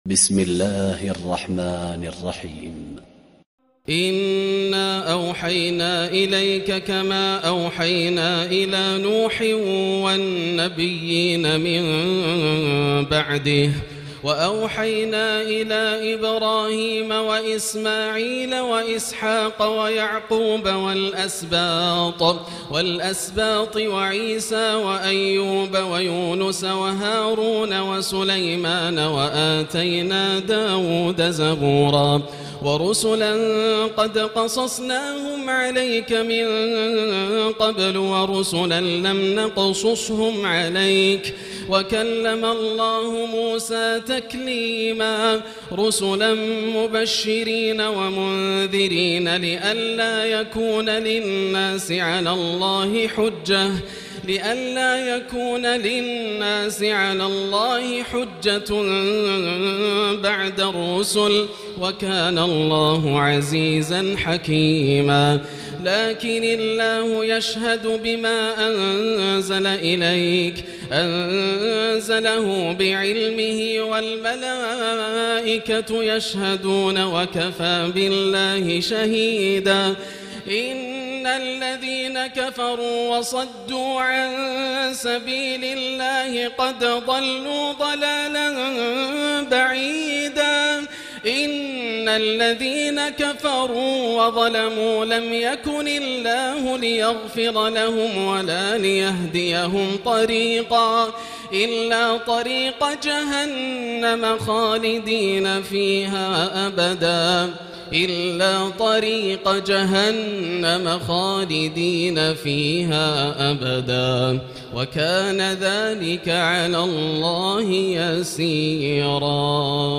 الليلة الخامسة ختام رائع لسورة النساء 163-176 وافتتاح بديع لسورة المائدة1-40 > الليالي الكاملة > رمضان 1439هـ > التراويح - تلاوات ياسر الدوسري